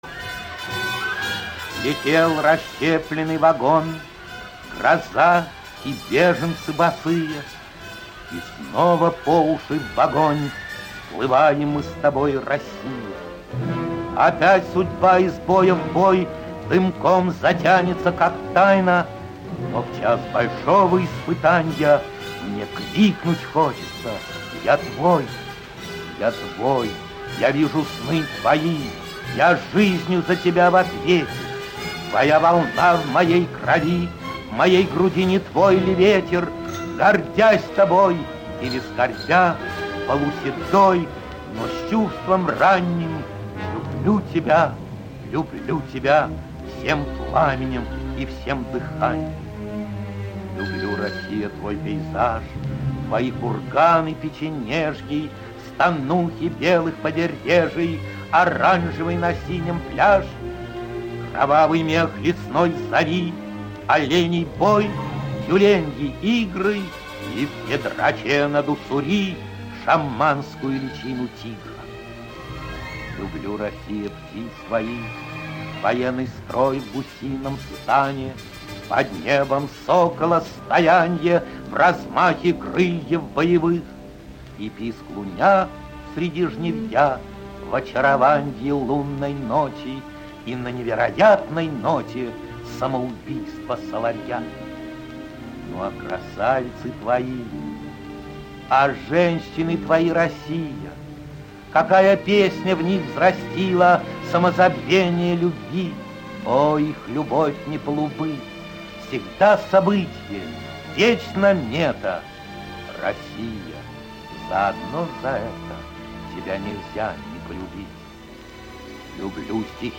Илья Сельвинский – России (читает автор)